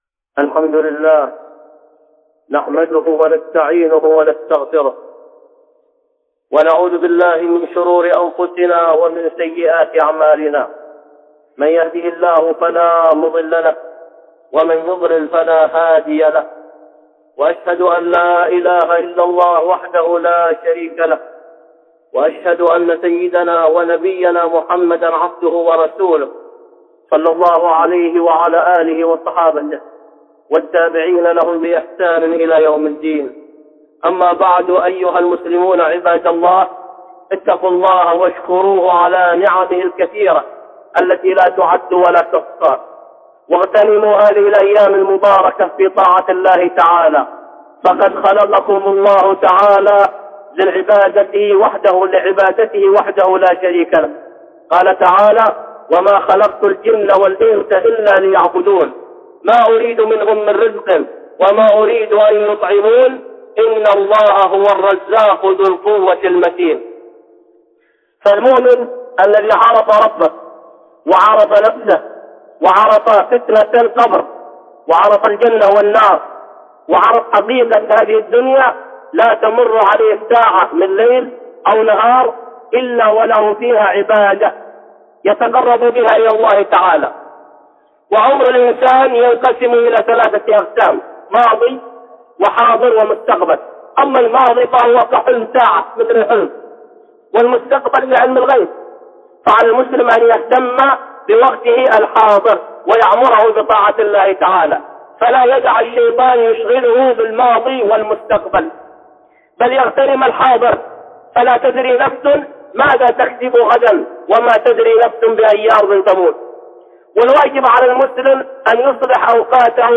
(خطبة جمعة) حقيقة العبادة والصدق في الطاعه